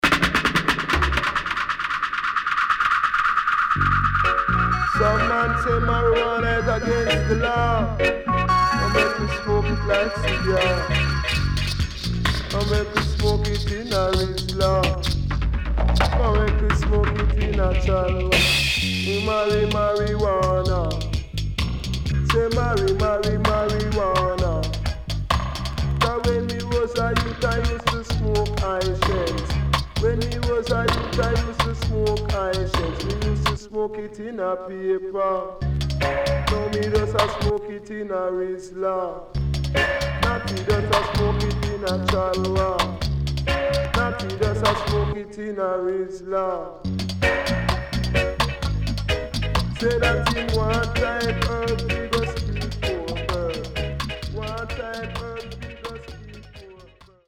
HOME > LP [VINTAGE]  >  70’s DEEJAY
OLD SCHOOL Deejay